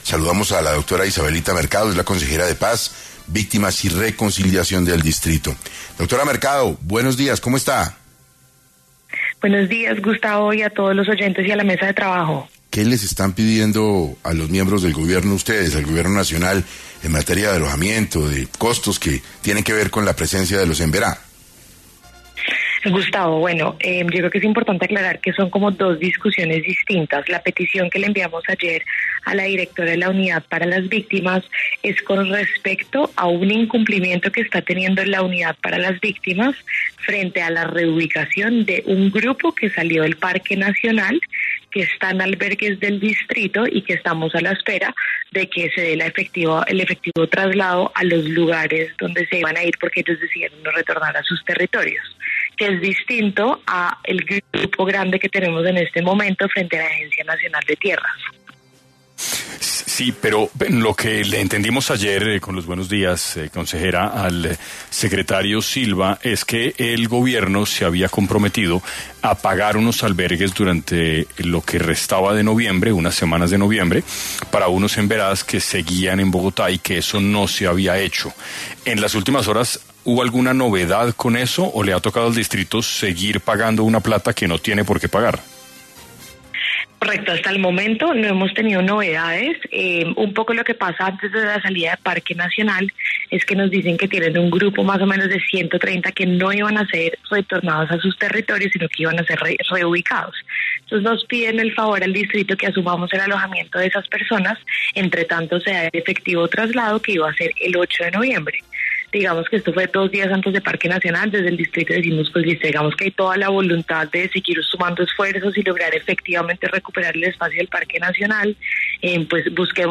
En 6AM de Caracol Radio estuvo Isabelita Mercado, consejera de Paz, Víctimas y Reconciliación del Distrito, para hablar sobre por qué es importante que el Gobierno asuma los costos de alojamiento de la comunidad Emberá de Bogotá.